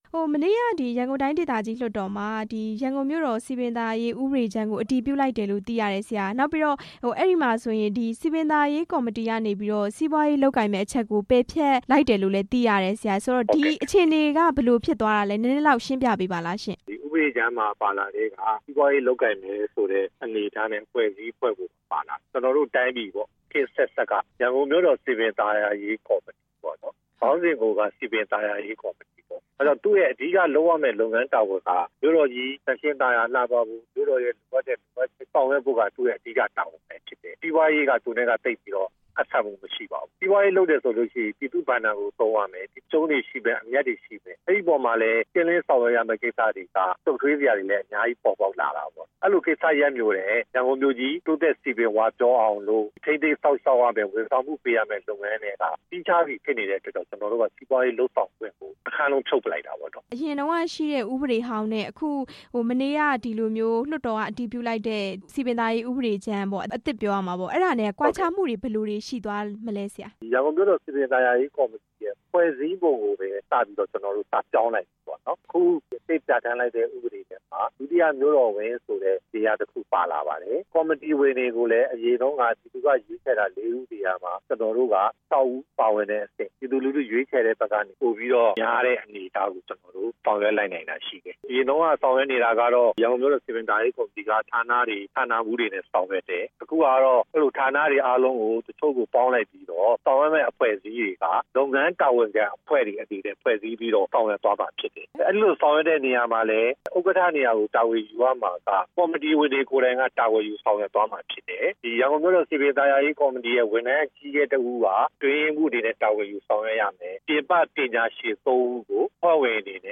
မေးမြန်းခန်း